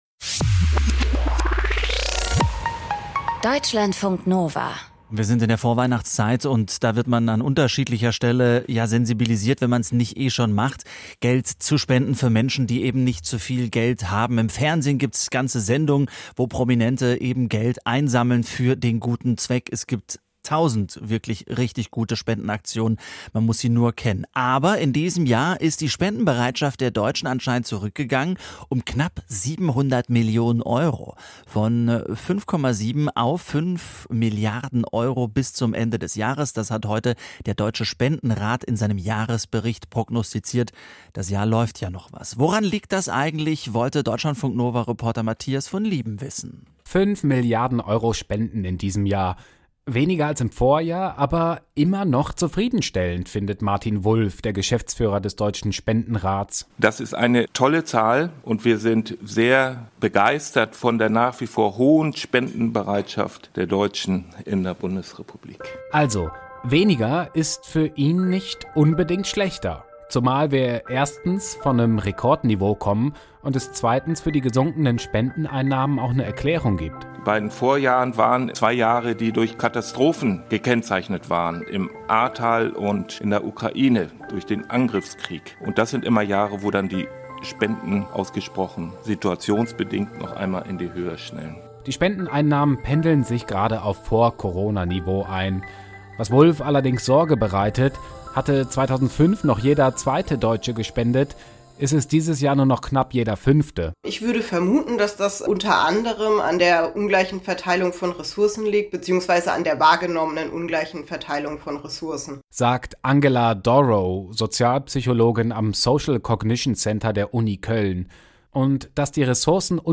Oft fehle ein passendes digitales Angebot etwa für jüngere Menschen. Aus dem Podcast Interview Podcast abonnieren Podcast hören Podcast Interview Das Interview im Deutschlandfunk Kultur greift kulturelle und politische Trends ebenso auf wie...